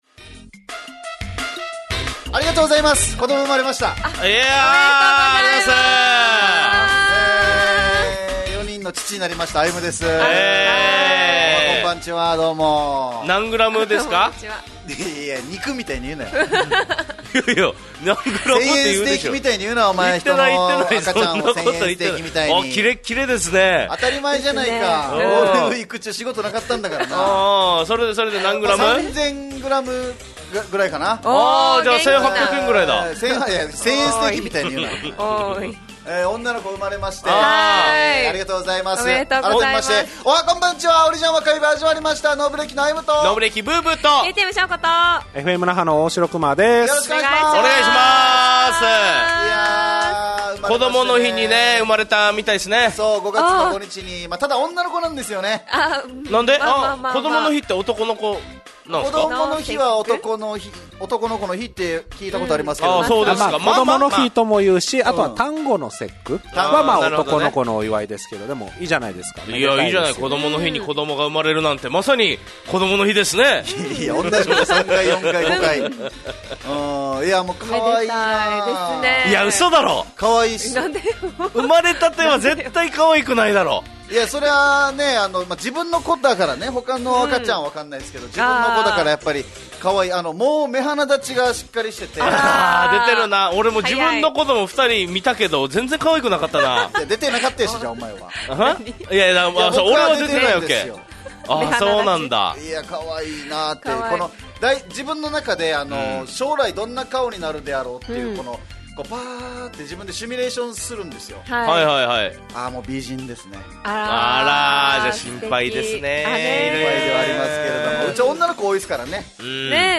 fm那覇がお届けする沖縄のお笑い集団・オリジンメンバー出演のバラエティ